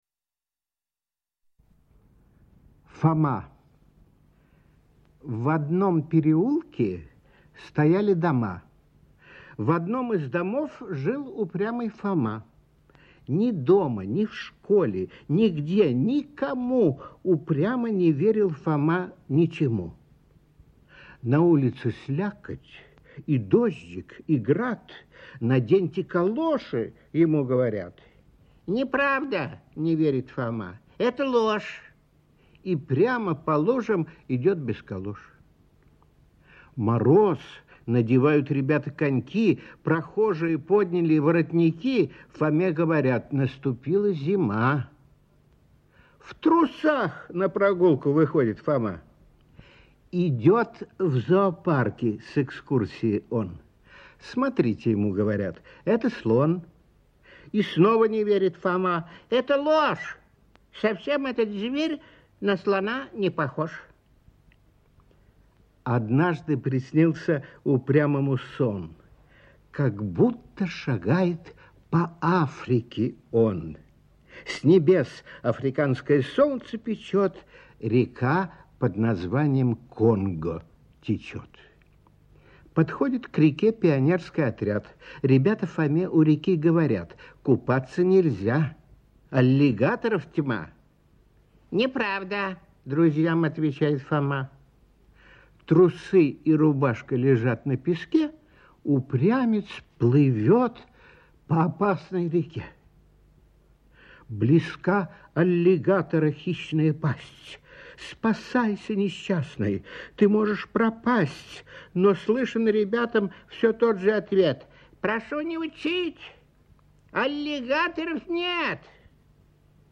7. «С.Михалков – Фома (читает Н.Литвинов)» /